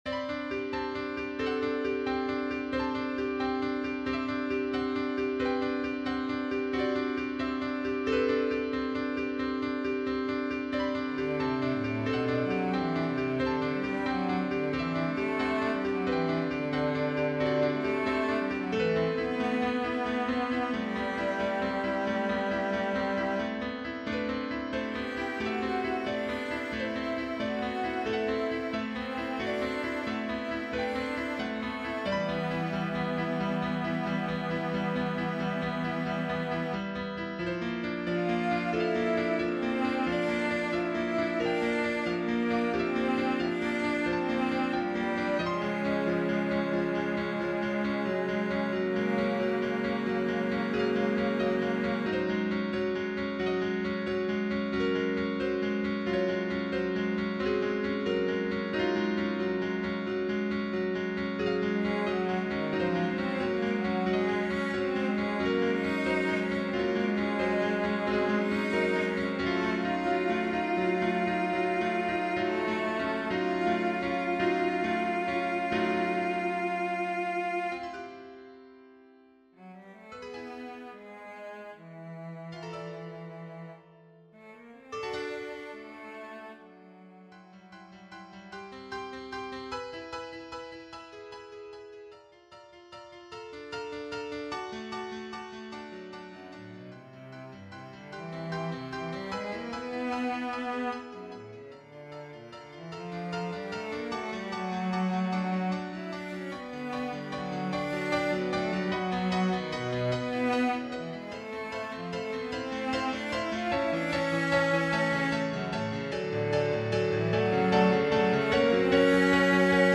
Sentiments - Chamber Music